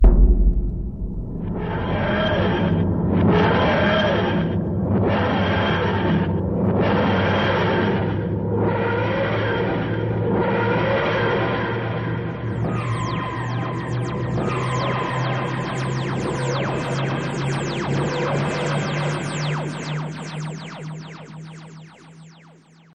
tardisoriganallaunch_24811.mp3